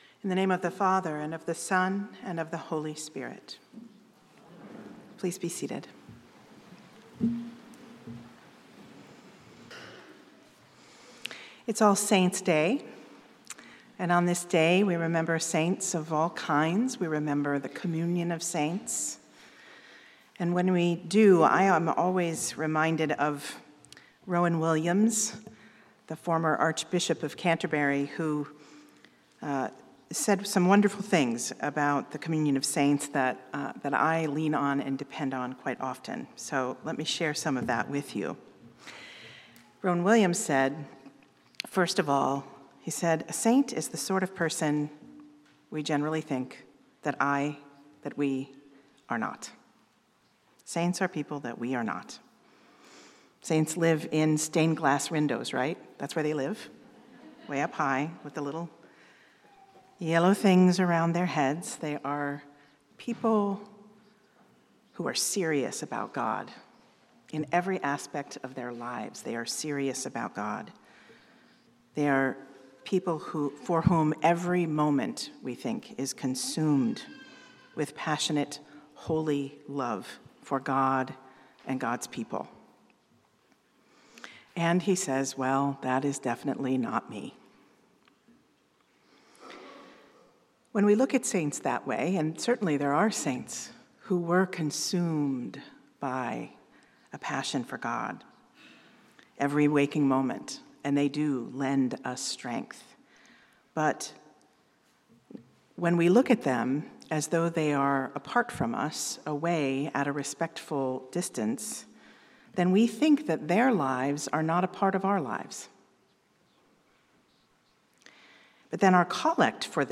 St-Pauls-HEII-9a-Homily-02NOV25.mp3